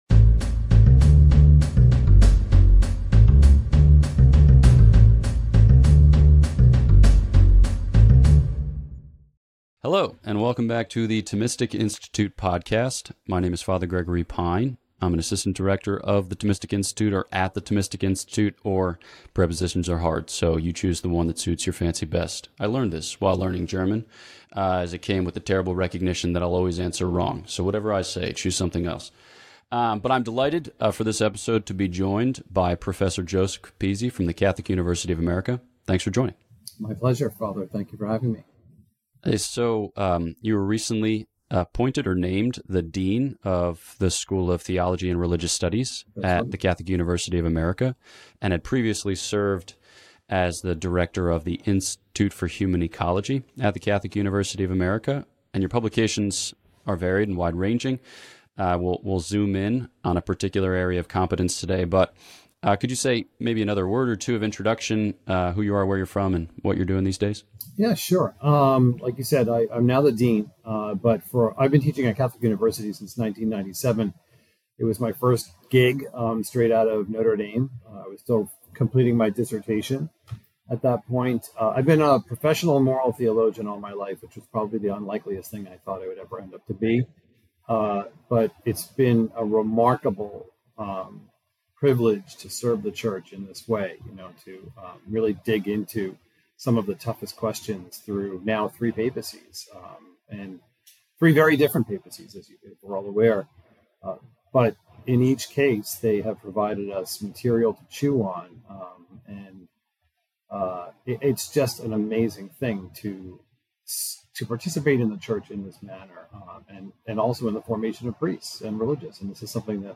This lecture was given on January 9th, 2024, at North Carolina State University.